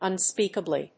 音節ùn・spéak・a・bly 発音記号・読み方
/‐kəbli(米国英語)/